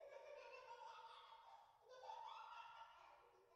中庭の主任室にあるパズルボックスで、太陽→太陽→星→太陽→月→星→太陽→月→太陽→月→星→月の順に入力すると、赤ちゃんの笑い声が再生される